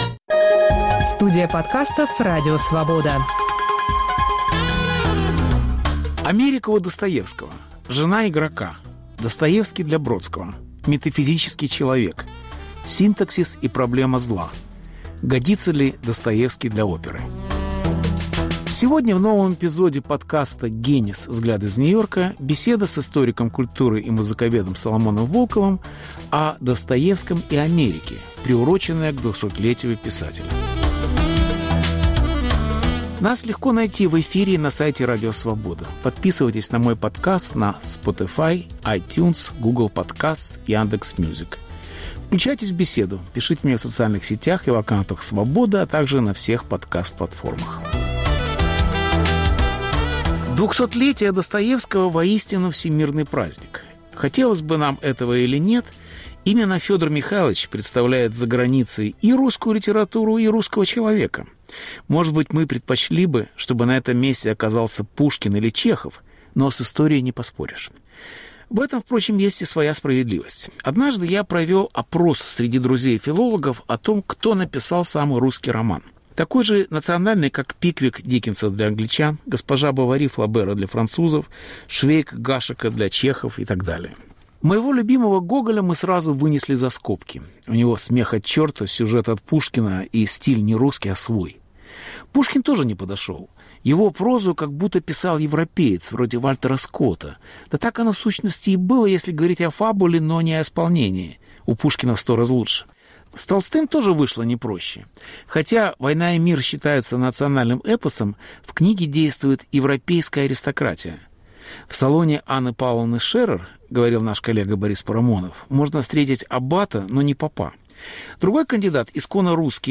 Беседа с Соломоном Волковым, приуроченная к 200-летию любимого русского классика на Западе